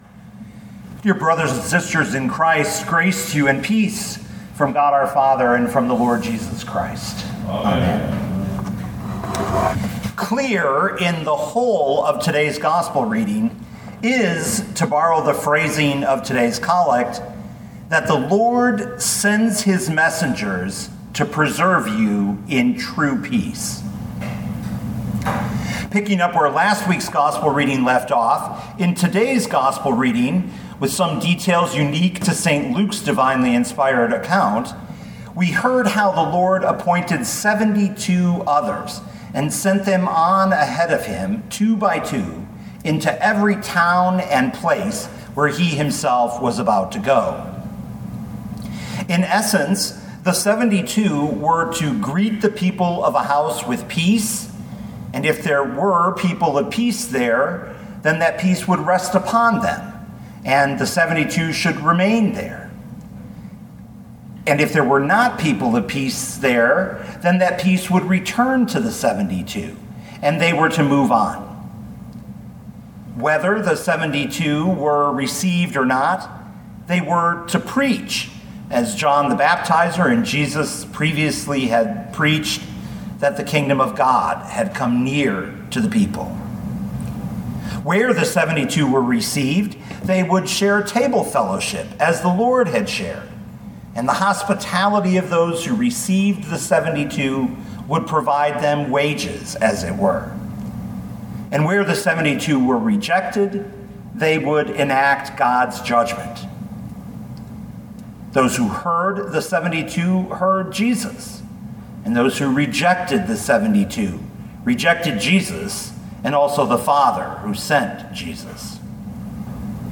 2022 Luke 10:1-20 Listen to the sermon with the player below, or, download the audio.